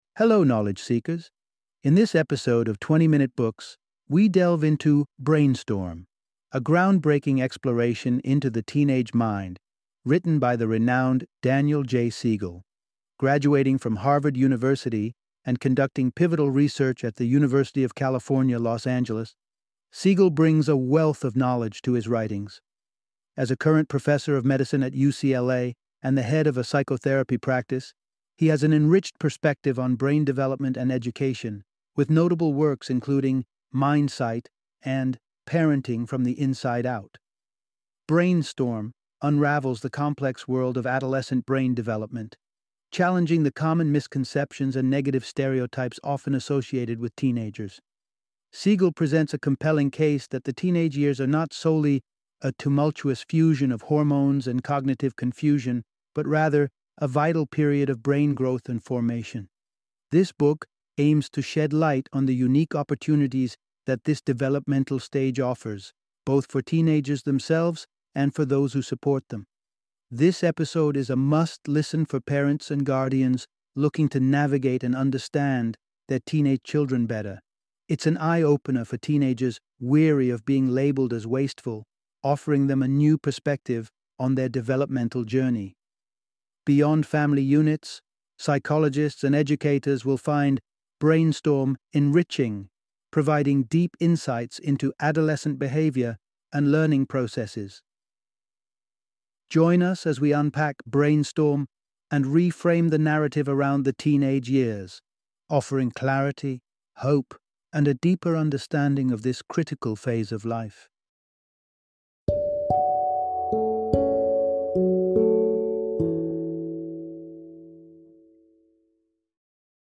Brainstorm - Book Summary